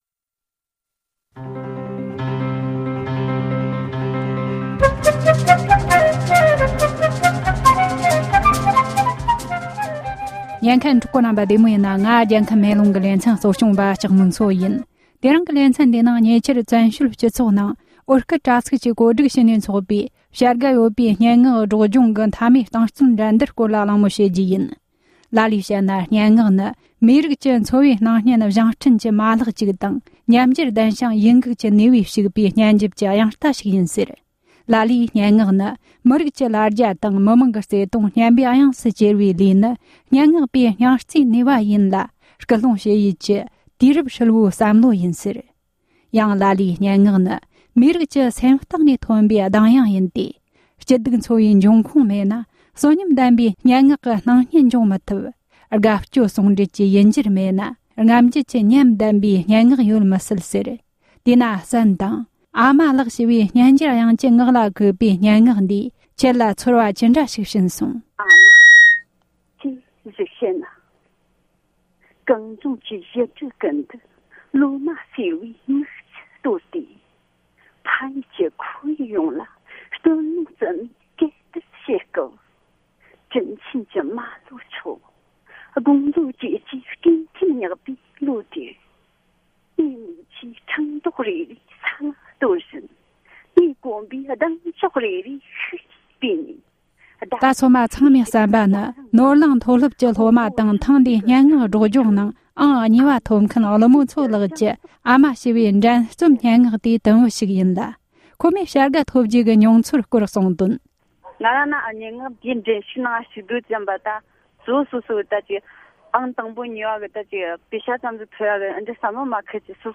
སྒྲ་ལྡན་གསར་འགྱུར། སྒྲ་ཕབ་ལེན།
འབྲེལ་ཡོད་མི་སྣ་ཁ་ཤས་ལ་བཅར་འདྲི་བྱས་པར་ཉན་རོགས་གནོངས།།